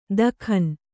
♪ dakhan